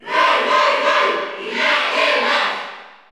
File:Roy Koopa Cheer Spanish PAL SSBU.ogg
Category: Crowd cheers (SSBU) You cannot overwrite this file.
Roy_Koopa_Cheer_Spanish_PAL_SSBU.ogg